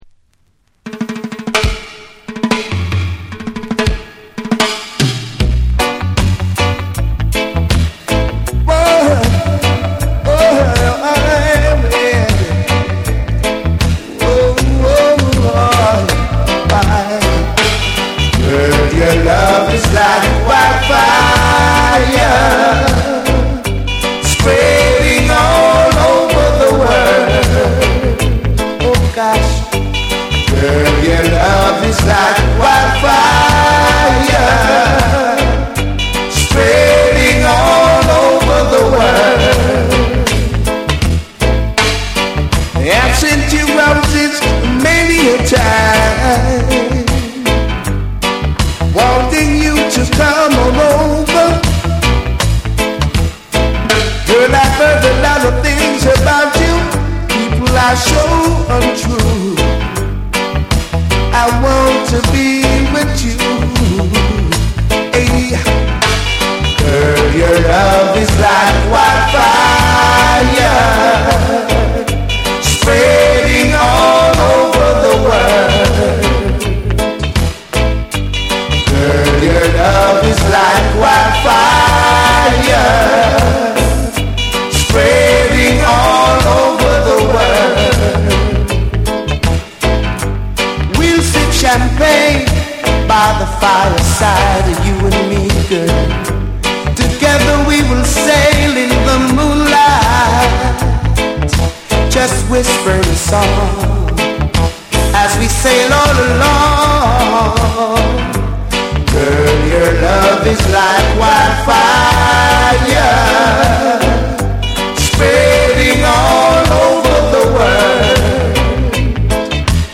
SOUL & FUNK & JAZZ & etc / REGGAE & DUB / TECHNO & HOUSE